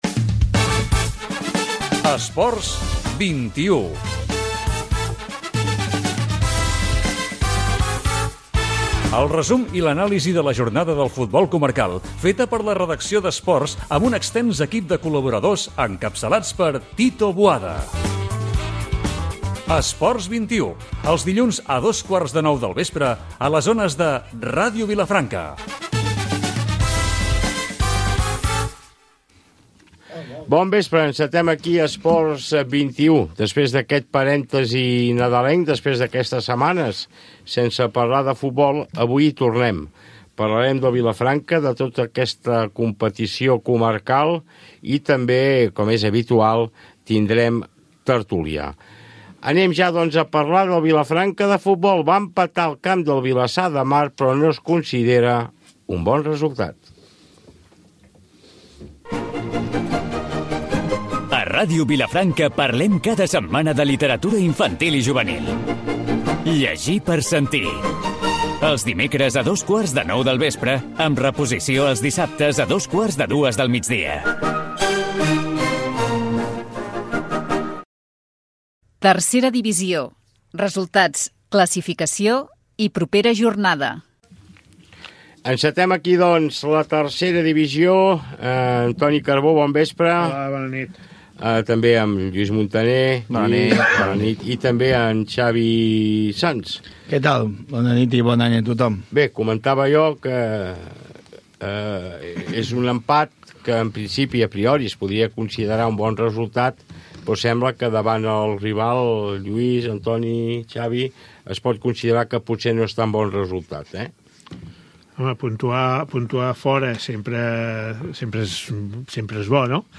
Careta del programa, sumari de continguts,promoció del programa "Llegir per sentir", tertúlia sobre els resultats de la tercera divisió de futbol masculí i l'empat del Vilafranca de Futbol al camp del Vilassar
Esportiu